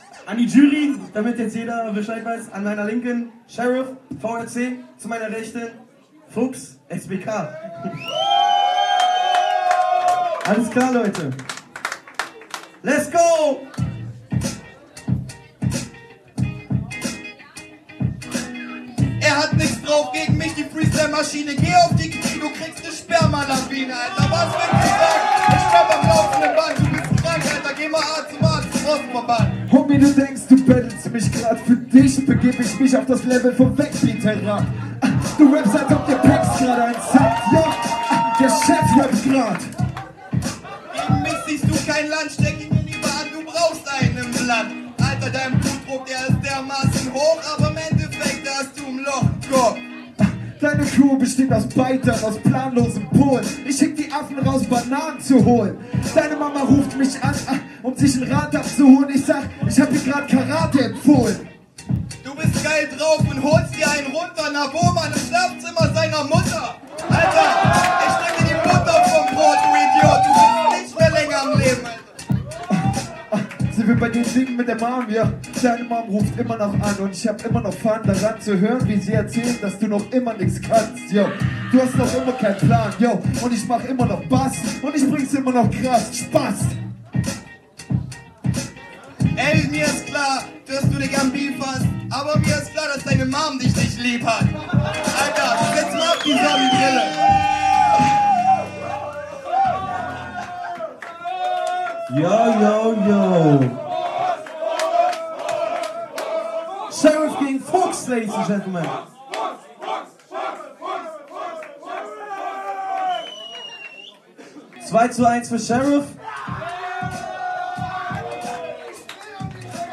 an den Turntables